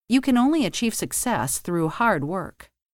O “gh” é mudo: /θruː/